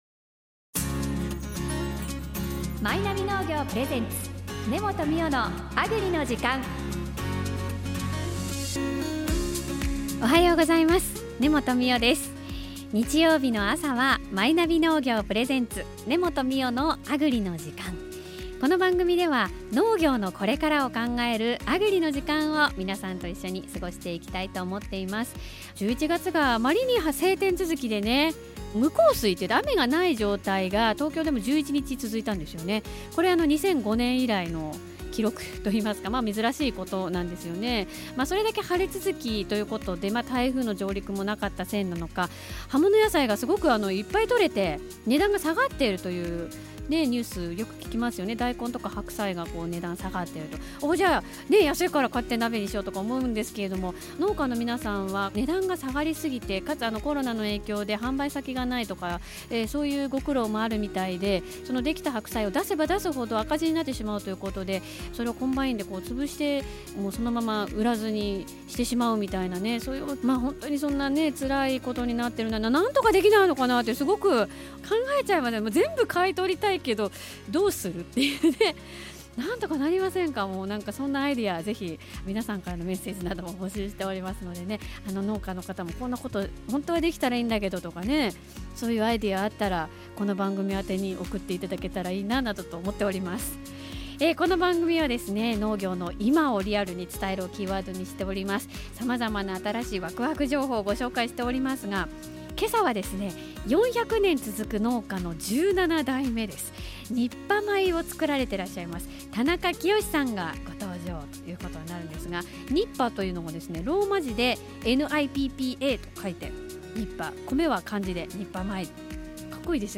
農作業の合間に、オンラインでご出演いただきました。